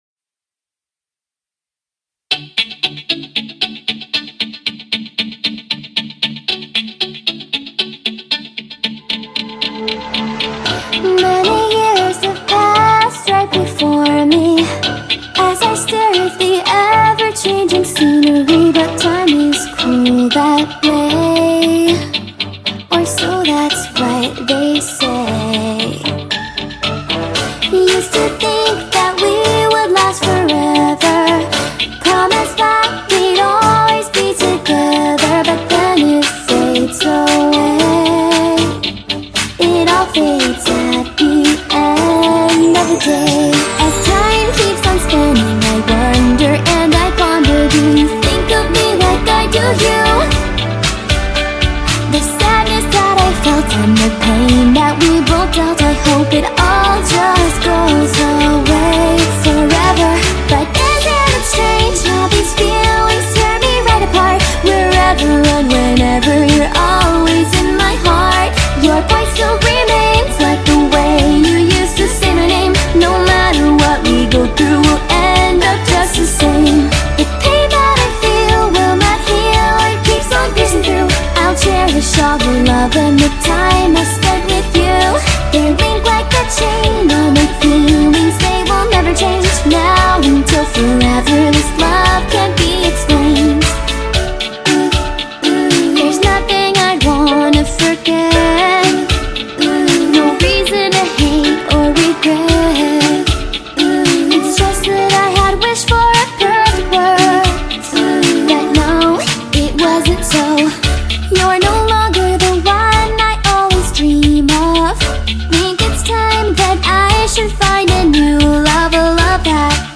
BPM80-115
Audio QualityCut From Video